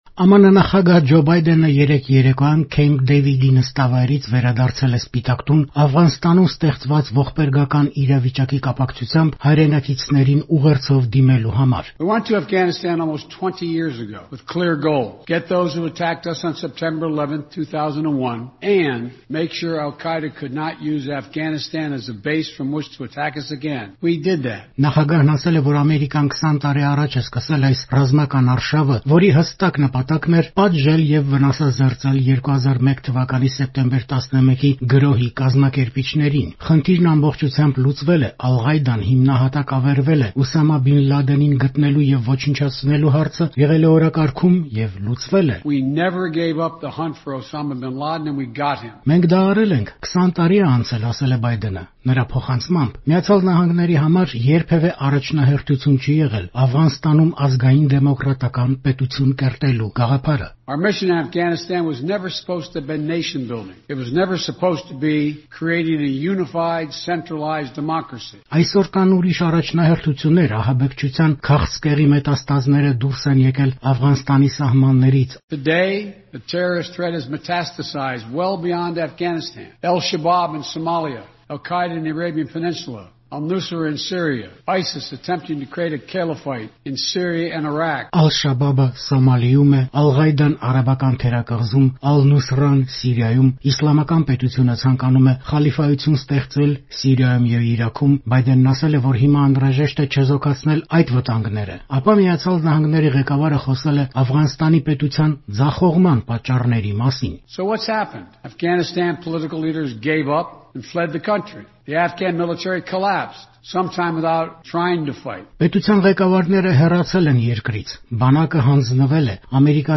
Ռեպորտաժներ
«Ամերիկան ավարտում է իր ամենաերկարատև պատերազմը». Բայդենն ուղերձով դիմեց հայրենակիցներին